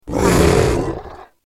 جلوه های صوتی
دانلود صدای گربه بزرگ و ترسناک از ساعد نیوز با لینک مستقیم و کیفیت بالا